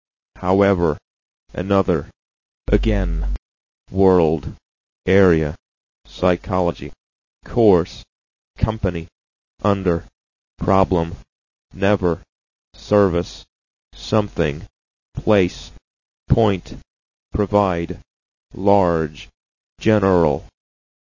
Demonstration of phonetic transcription
All the pronunciations are written with “universal” (British-American) symbols, but are spoken in American English. For example, their is transcribed as /ðeəʳ/, and the r sound is heard in the recording.